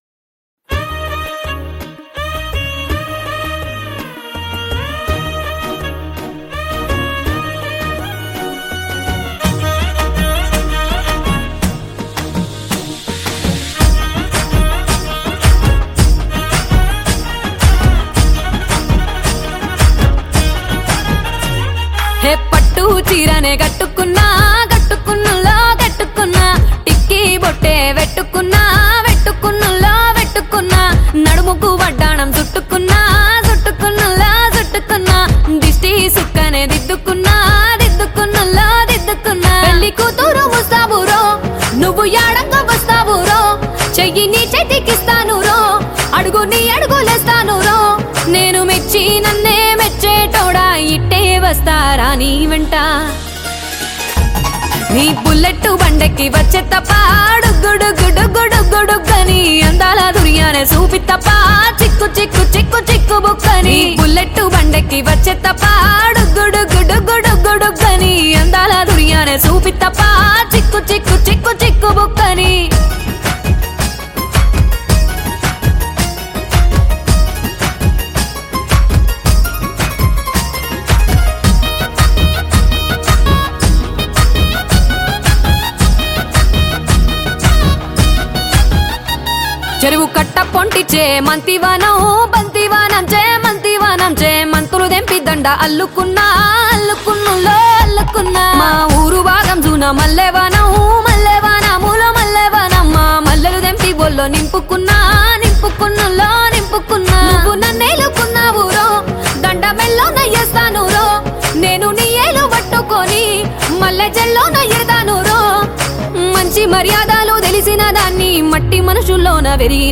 Telugu Songs
Full song dance